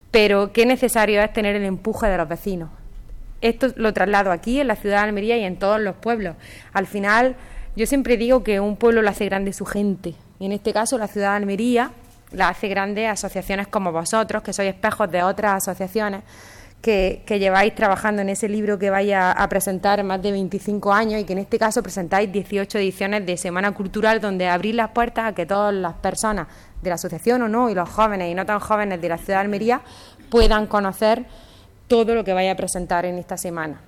En el acto, que se ha celebrado en el Patio del Mandarino, ha intervenido la vicepresidenta y diputada de Cultura, Cine e Identidad Almeriense, Almudena Morales